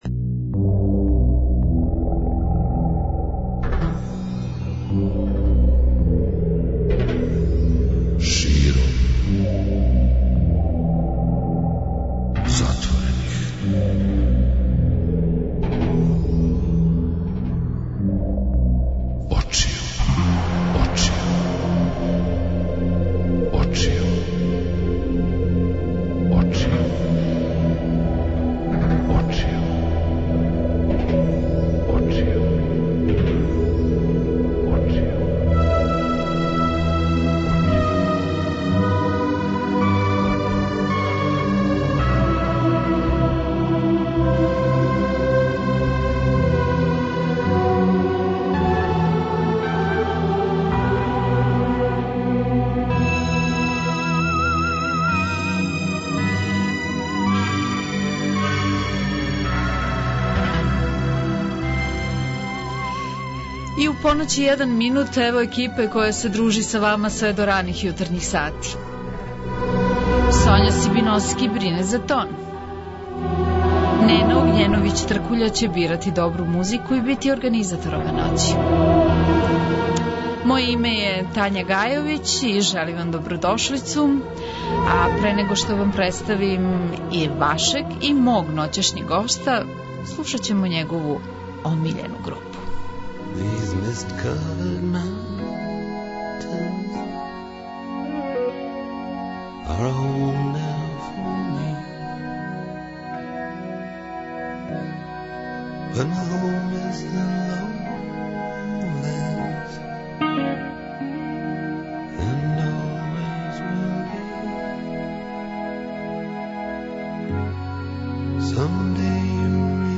Гост: Ненад Милосављевић, познатији као Неша Галија, музичар
Укључите се и ви у разговор.